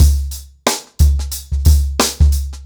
TheStakeHouse-90BPM.27.wav